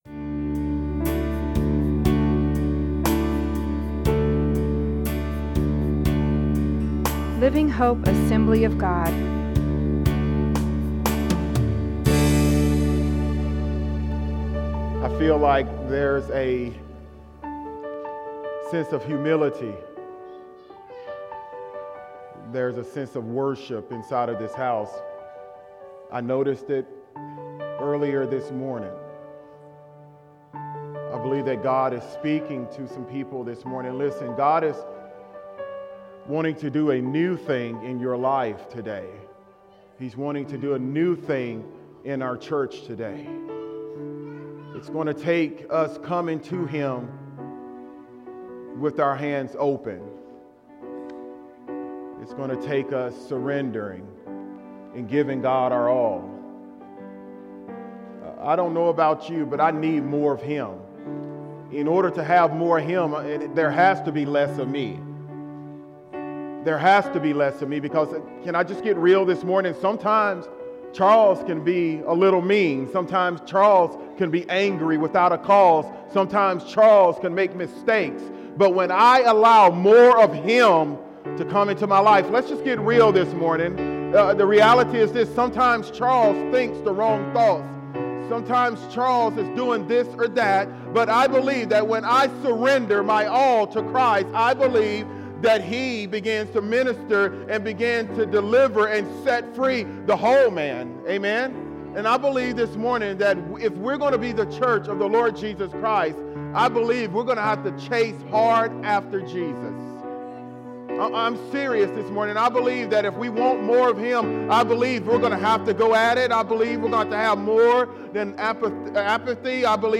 We encourage you to take the time to listen to the sermon and drop us a note to let us know how it ministered to you.